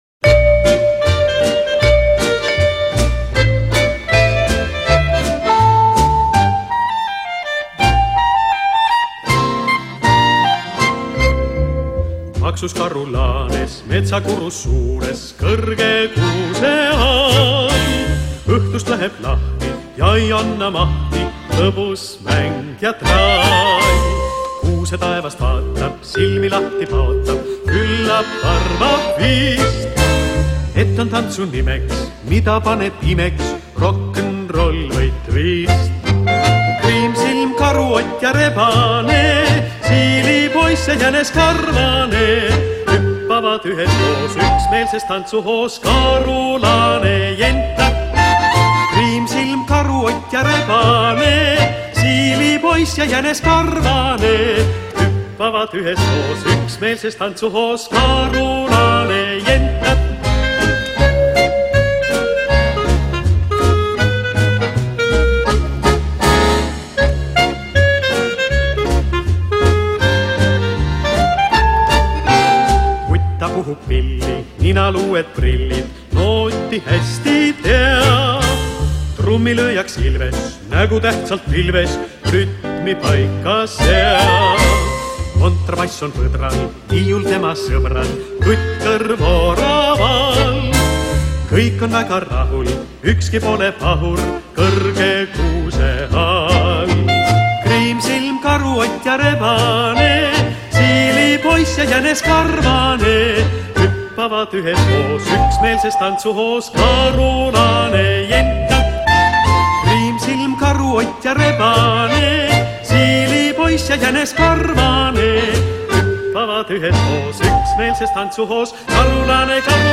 karulaane_jenka.mp3